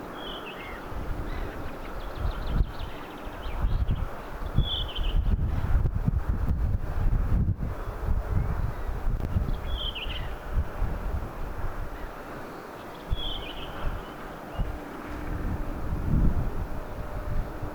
erikoisen lyhyttä punakylkirastaslinnun laulutyyppiä
tuollaista_lyhytta_punakylkirastaslinnun_laulua.mp3